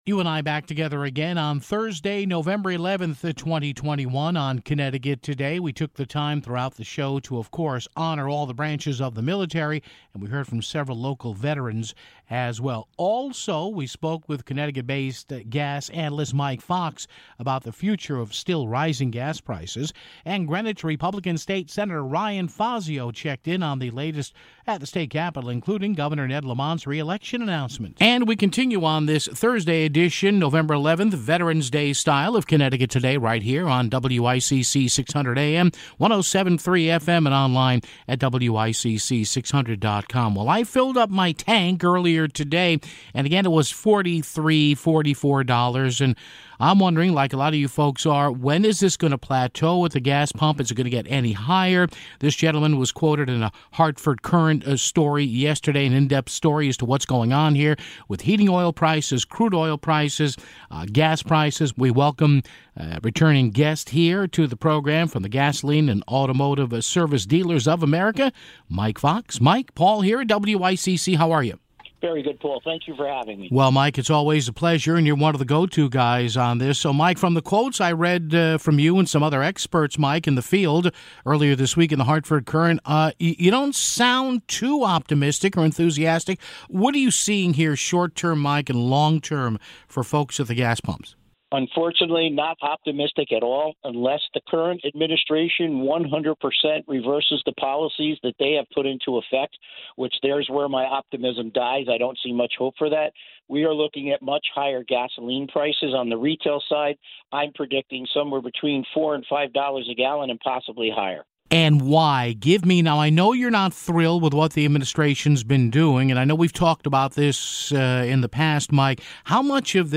Greenwich GOP State Sen. Ryan Fazio checked in on the latest at the State Capitol, including Gov. Ned Lamont's reelection announcement (10:25).